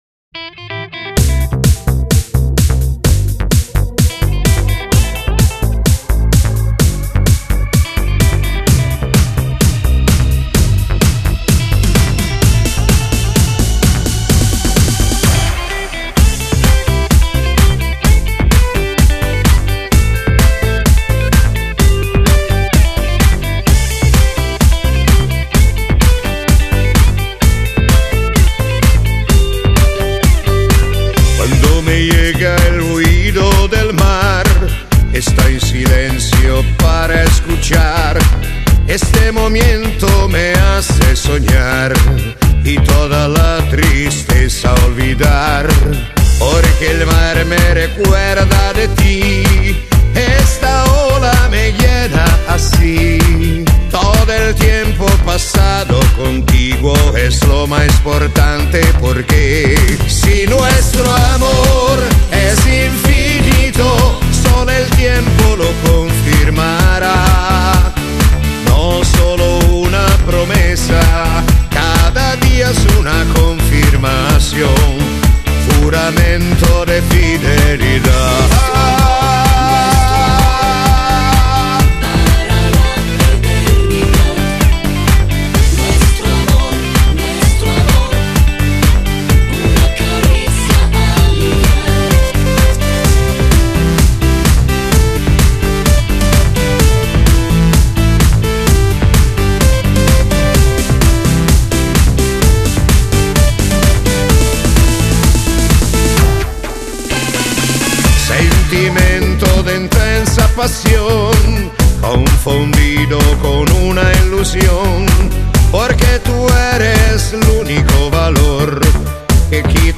Genere: Bachata disco